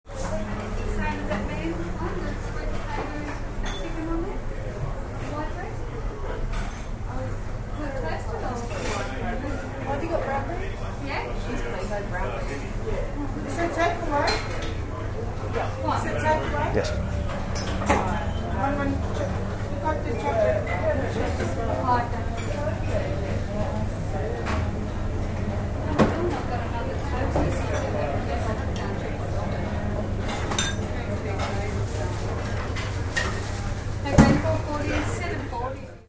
I have several hours of nice field recordings on mini-disc, some of which will eventually make it up here as mp3s, but this will happen gradually.
• Lunchtime at a sandwhich shop at Circular Quay, Sydney, September 22.
sandwhichshop-circular_quay.mp3